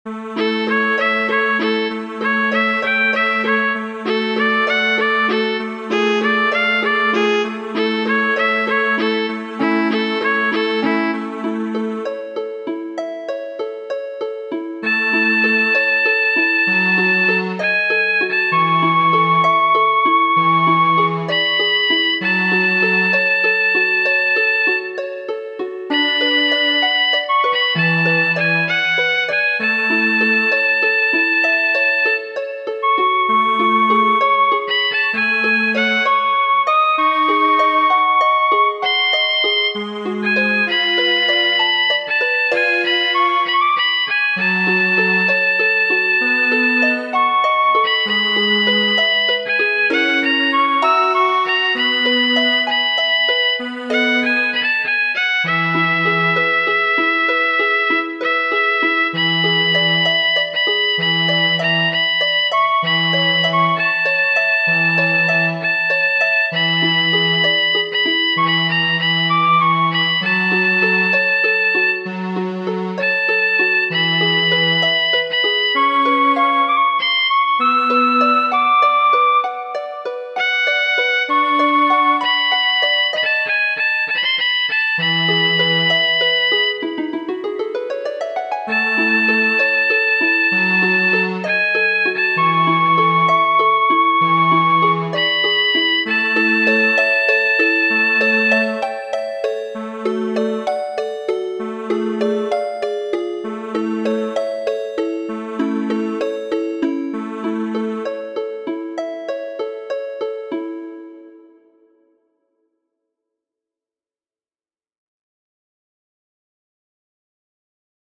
| Kajero 32 ª | En PDF (paĝo 1ª) | Kajeroj | Muziko : Saluton, Maria! , kanto de Franz Schubert.